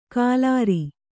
♪ kālāri